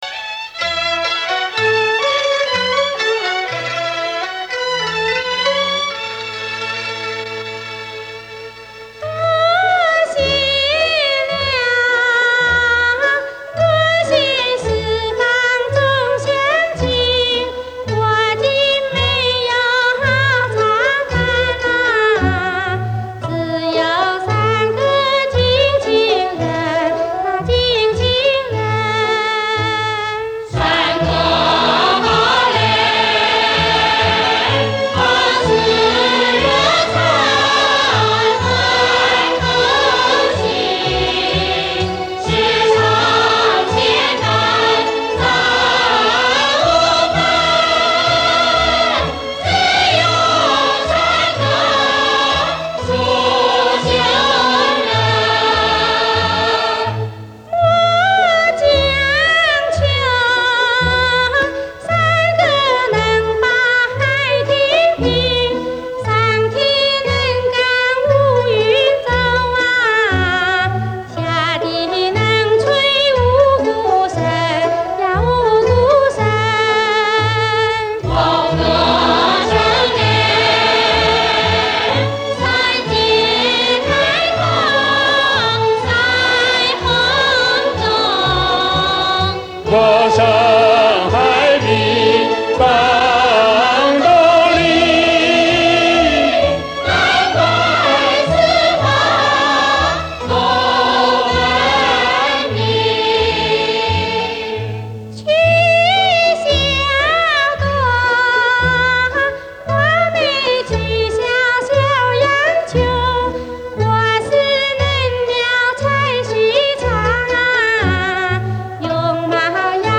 给大家换一下口味,推荐一张百听不厌山歌。